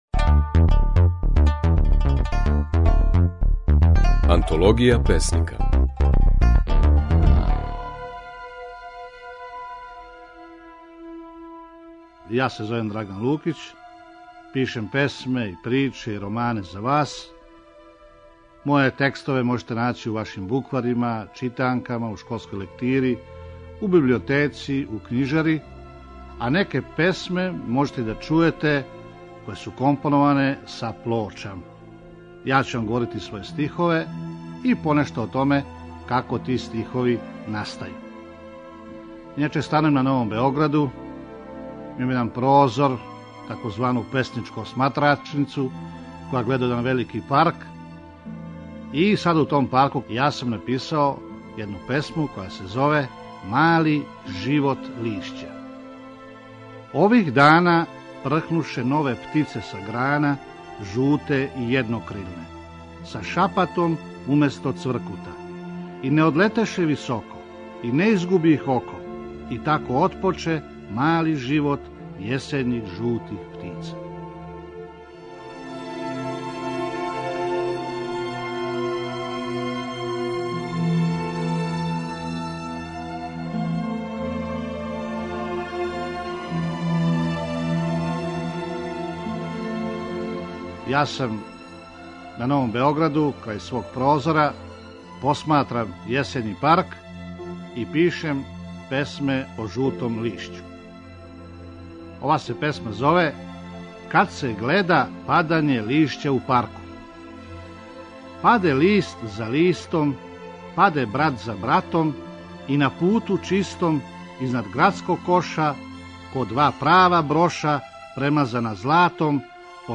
Можете да слушате како је своје песме говорио песник Драган Лукић (1928-2006).
Емитујемо снимке на којима своје стихове говоре наши познати песници